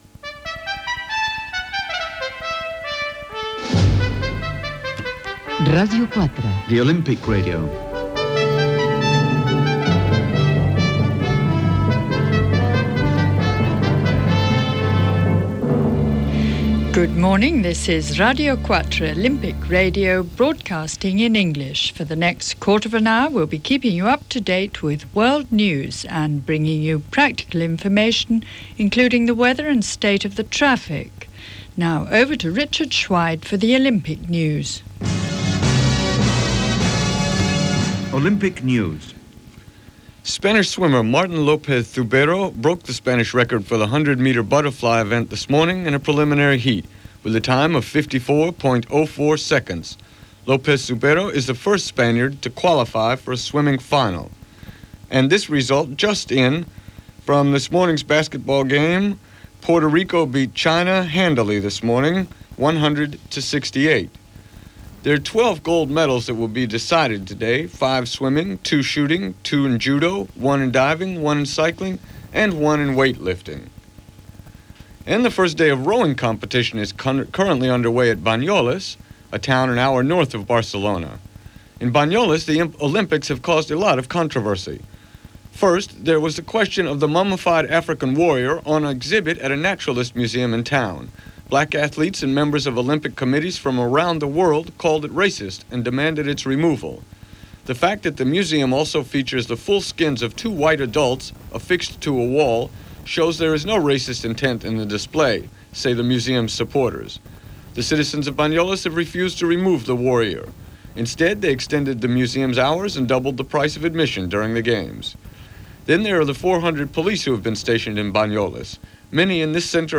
54418f62ec2601acb3c15439f90c0db2cd656b83.mp3 Títol Ràdio 4 la Ràdio Olímpica Emissora Ràdio 4 la Ràdio Olímpica Cadena RNE Titularitat Pública estatal Descripció Bloc en anglès: informació olímpica, tema musical, informació general, informació meteorològica i agenda cultural. Gènere radiofònic Informatiu Data emissió 1992-07-27 Banda FM Localitat Barcelona Comarca Barcelonès Durada enregistrament 15:10 Idioma Anglès Notes Informació preparada per l'equip del BBC World Service.